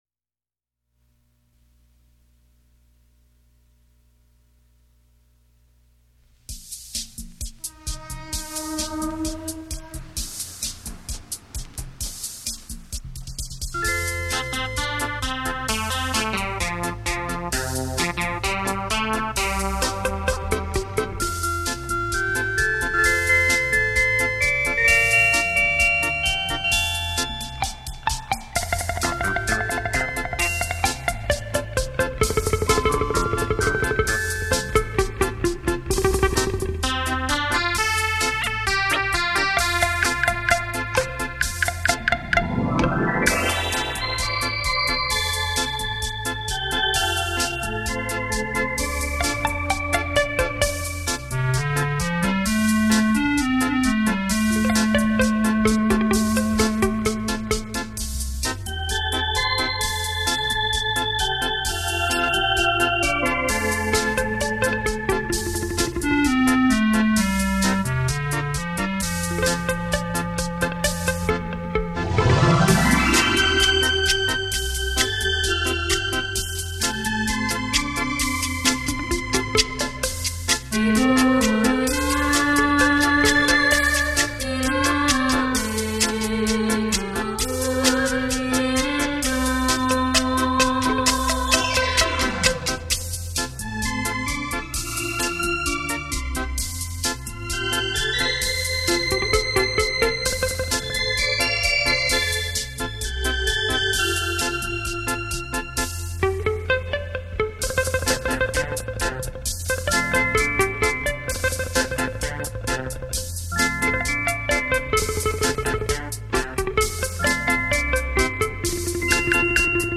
优美的旋律、清脆的音感